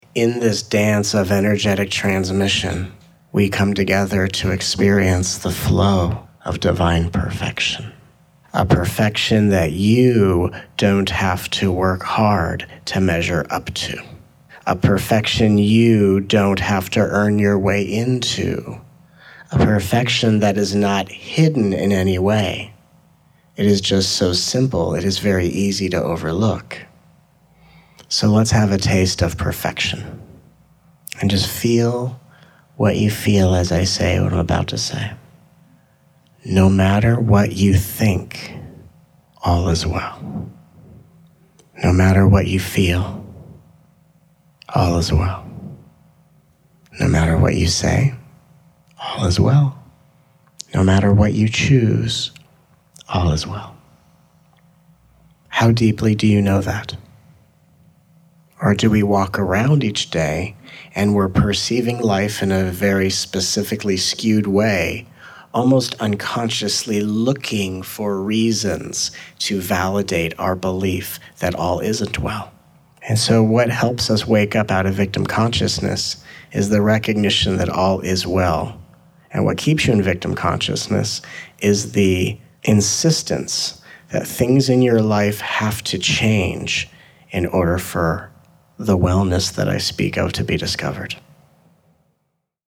This special package of teachings includes 7 hours of energetically-fueled downloads, this never-before-released collection of teachings clarifies all aspects of the spiritual journey with humor, compassion, and ease, including: